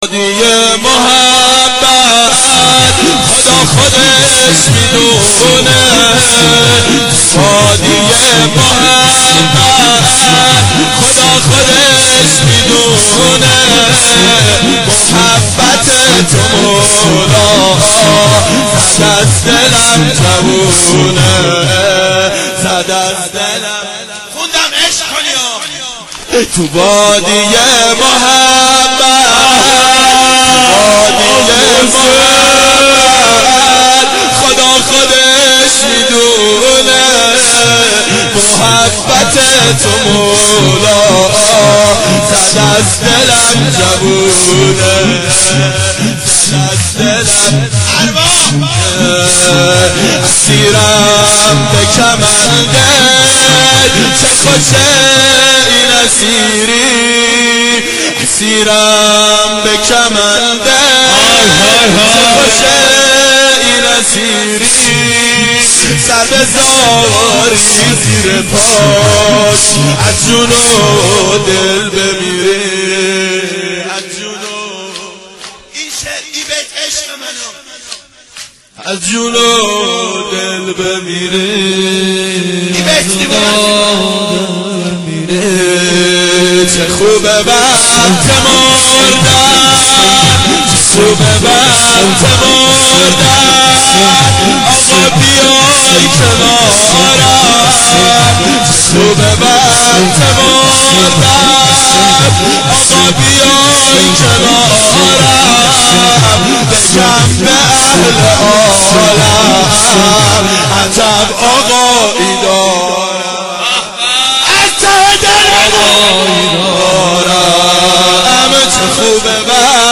اربعین 86 هیئت متوسلین به امیرالمؤمنین حضرت علی علیه السلام